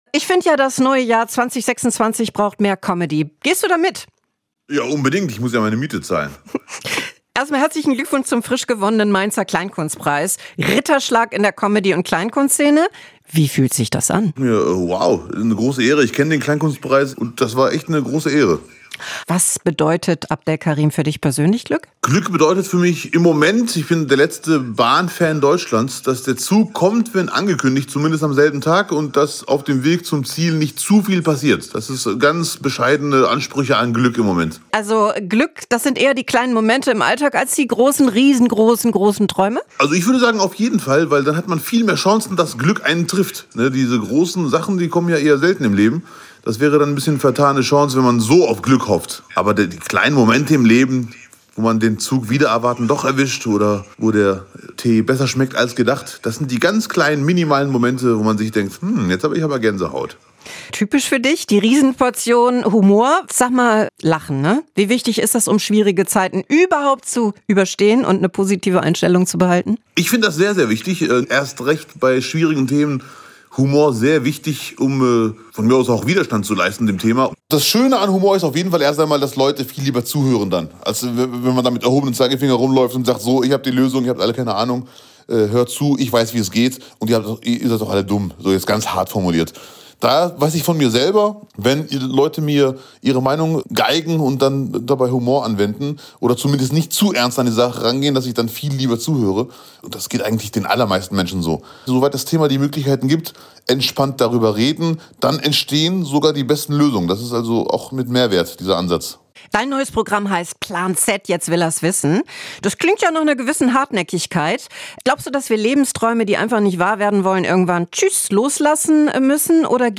Mehr SWR1 Interviews